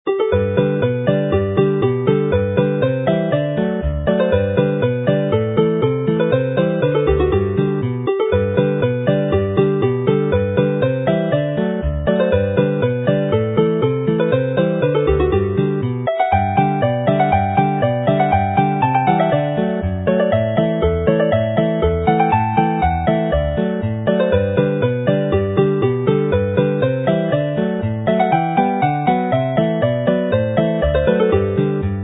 mp3 file as a polka, fast with chords